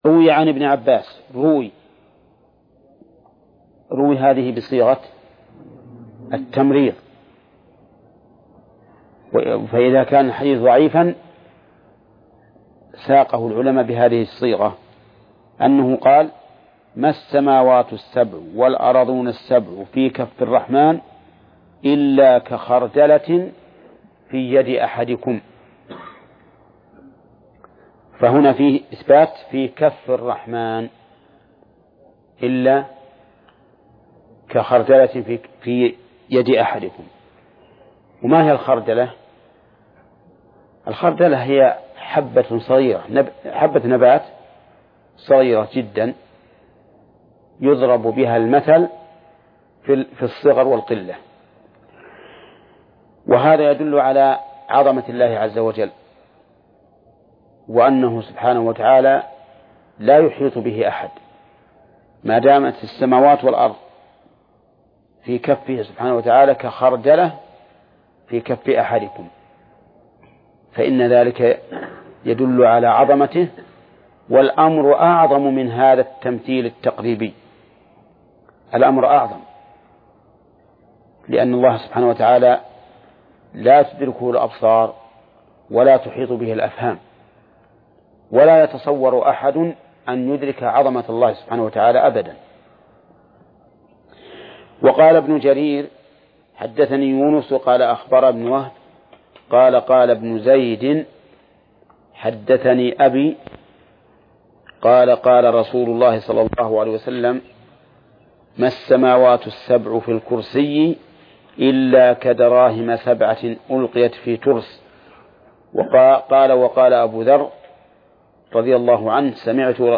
درس (55) / المجلد الثاني : من صفحة: (535)، قوله: (وروي عن ابن عباس: ..).، إلى: نهاية المجلد الثاني.